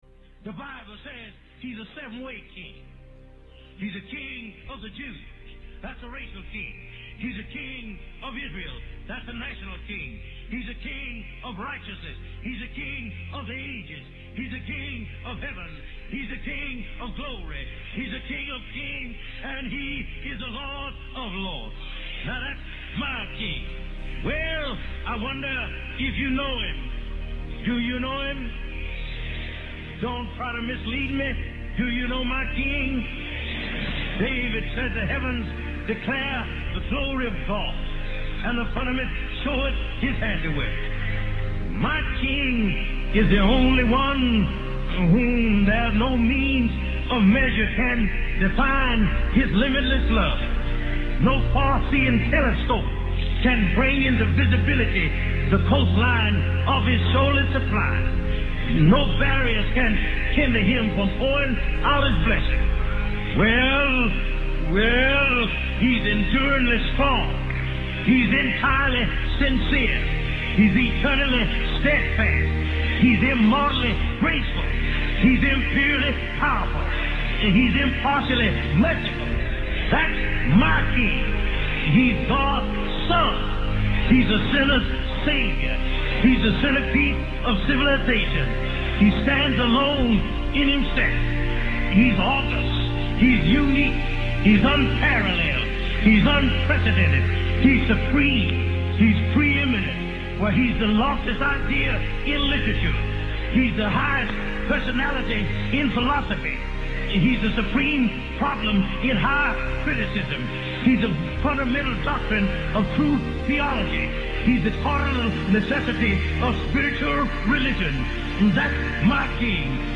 sermon compilation